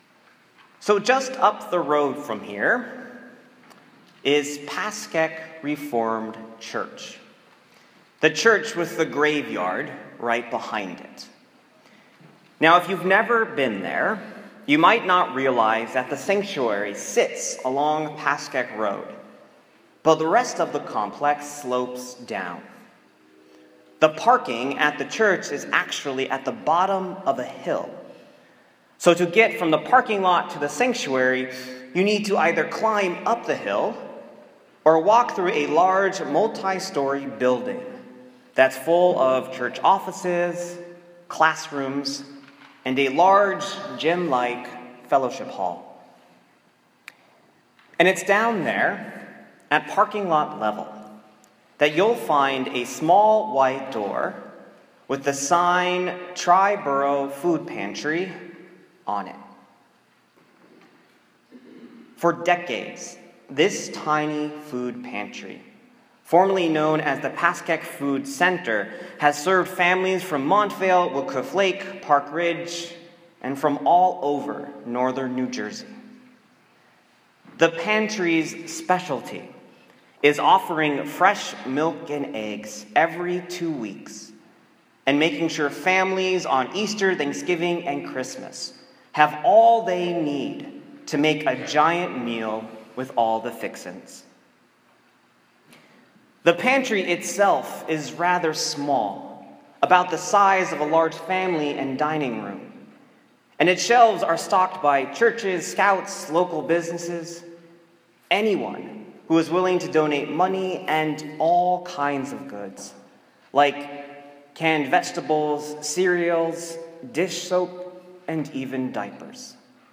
Ongoing, Continual: a sermon on God’s More
For we are God’s servants, working together; you are God’s field, God’s building. 1 Corinthians 3:1-9 My sermon from 6th Sunday after Epiphany (February 12, 2017) on 1 Corinthians 3:1-9.